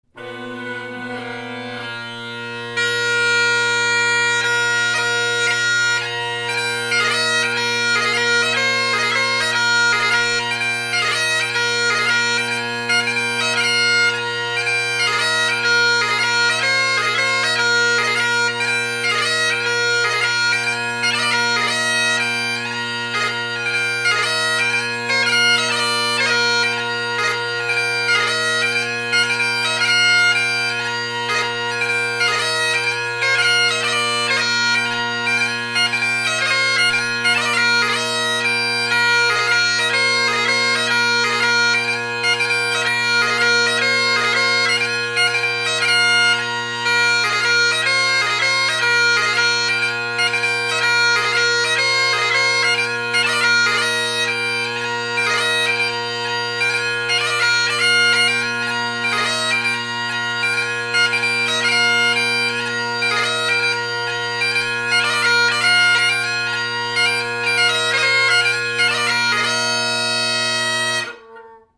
played faster